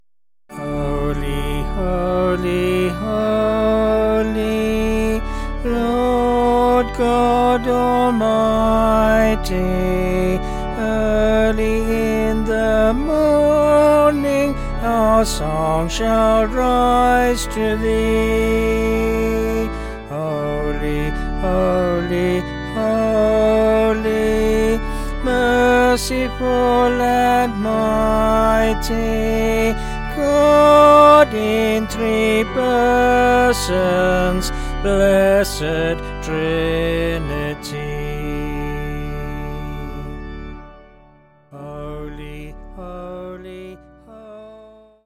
Vocals and Organ
705.4kb Sung Lyrics 2.9mb